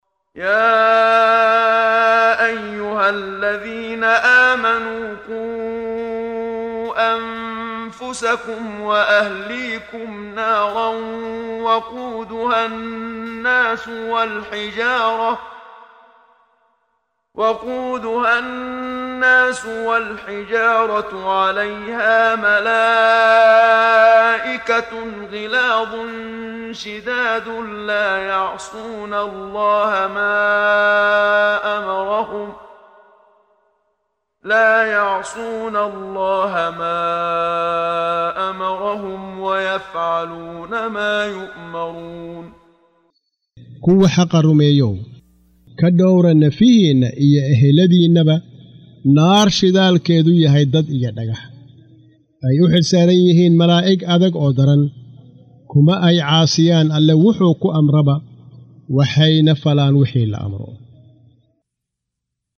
Waa Akhrin Codeed Af Soomaali ah ee Macaanida Surah At-Taxriim ( Iska xaaraantimeynta ) oo u kala Qaybsan Aayado ahaan ayna la Socoto Akhrinta Qaariga Sheekh Muxammad Siddiiq Al-Manshaawi.